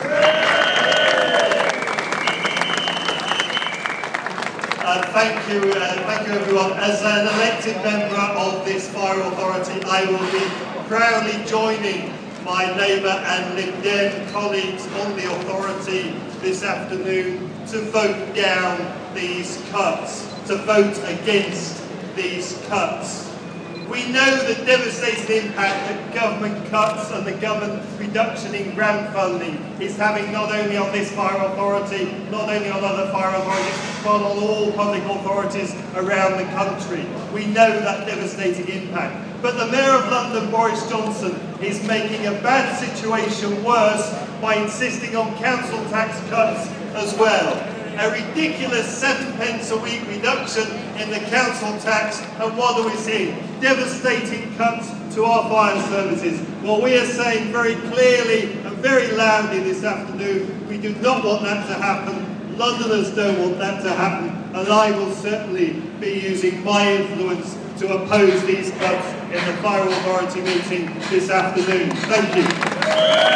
Darren Johnson AM speech at anti fire cuts demo
Union St